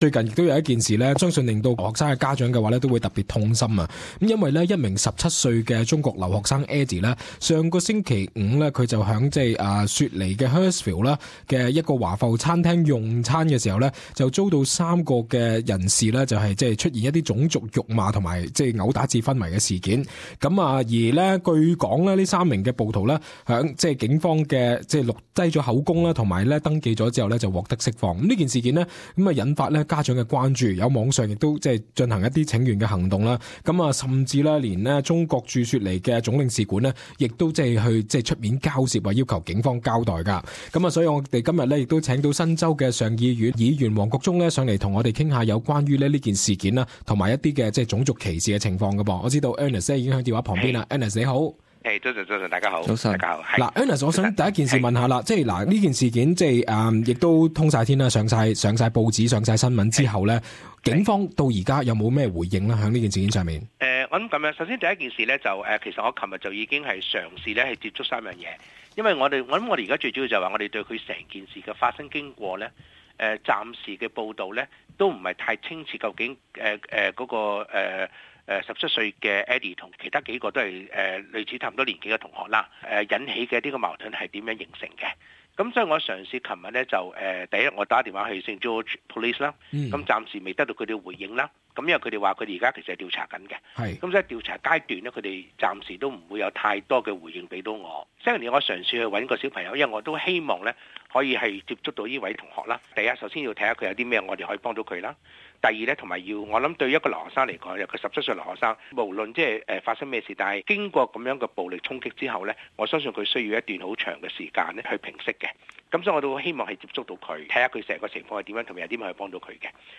Interview with Ernest Wong : further investigating is needed for Hurstville Chinese student afronted incident